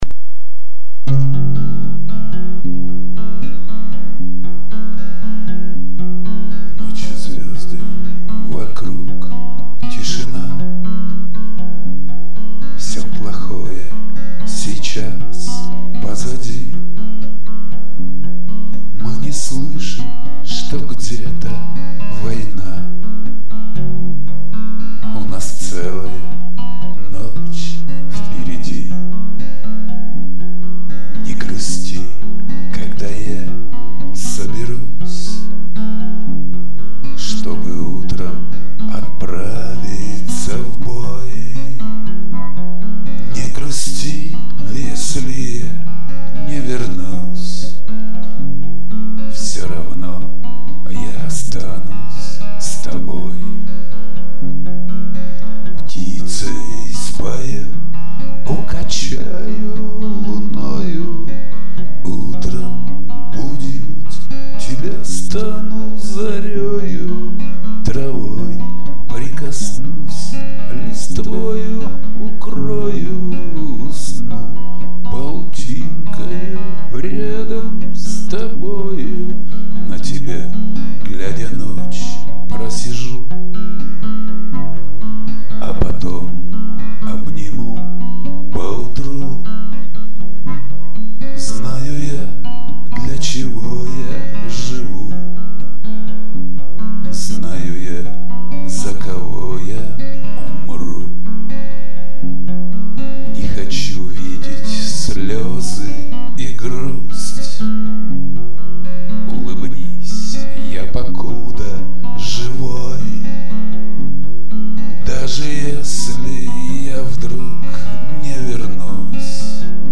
Удиветельно так похож тембр голоса
немного укачивает однообразный гитарный аккомпанемент